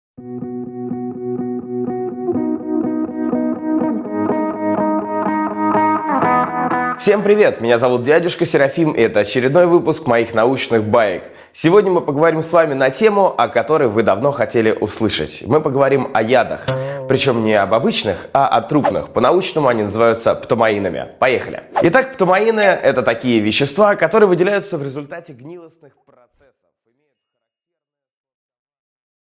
Аудиокнига Трупный яд | Библиотека аудиокниг